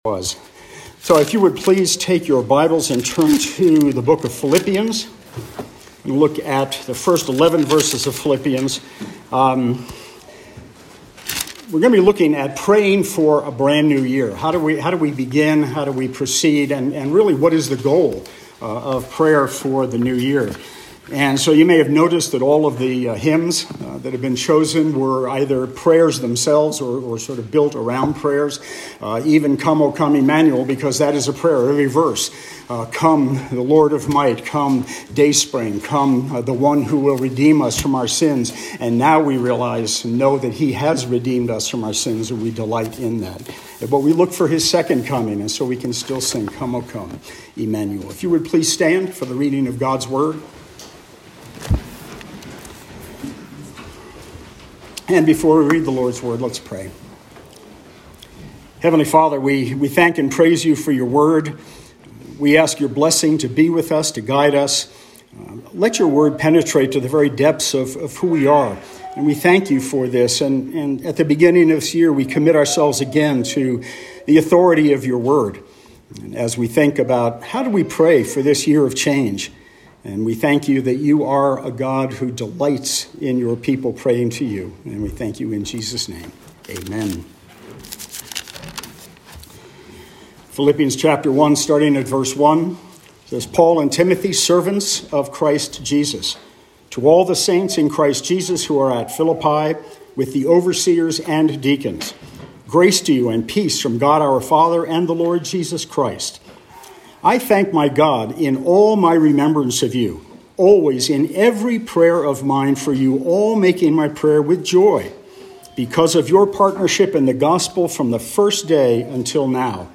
Guest Preacher Passage: Philippians 1:9-11 Service Type: Morning Service What do you want out of 2023?